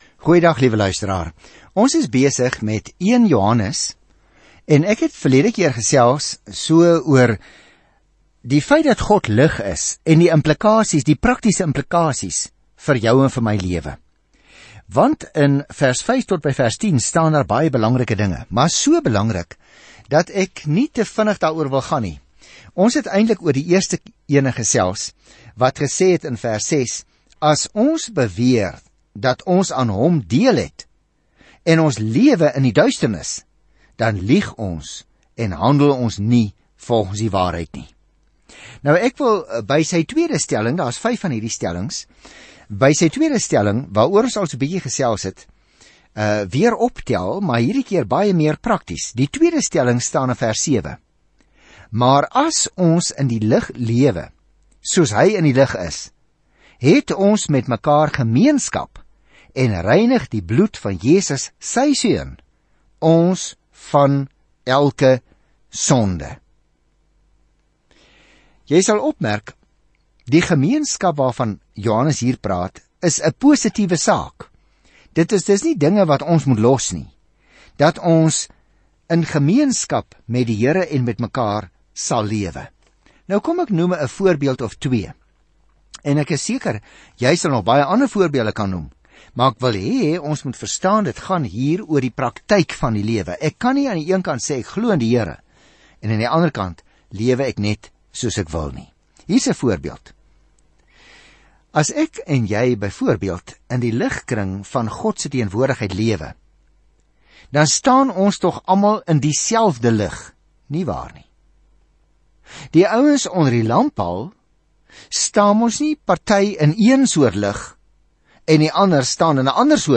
Skrif 1 JOHANNES 1:5-10 1 JOHANNES 2:1-2 Dag 3 Begin met hierdie leesplan Dag 5 Aangaande hierdie leesplan Daar is geen middeweg in hierdie eerste brief van Johannes nie - óf ons kies lig óf duisternis, waarheid tot leuens, liefde of haat; ons omhels die een of die ander, net soos ons die Here Jesus Christus óf glo óf verloën. Reis daagliks deur 1 Johannes terwyl jy na die oudiostudie luister en uitgesoekte verse uit God se woord lees.